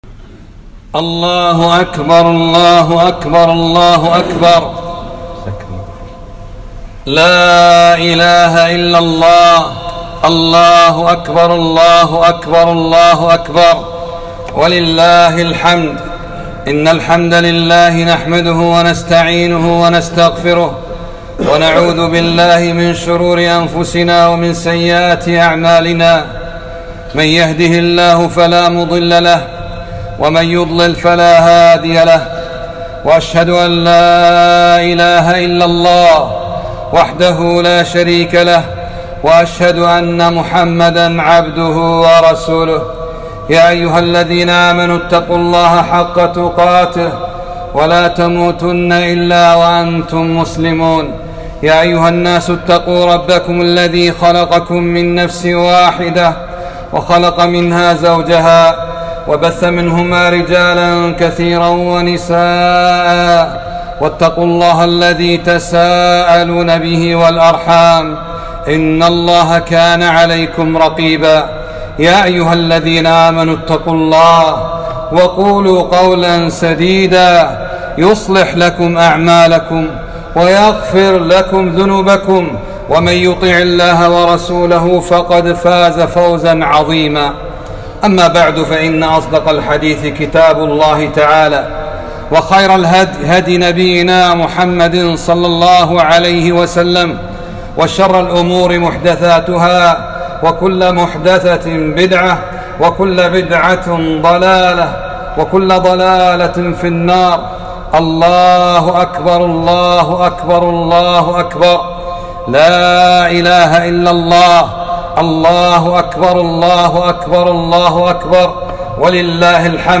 خُطبة عيد الفطر ١٤٤٢هـ